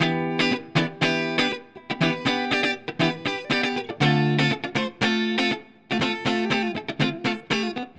29 Guitar PT2.wav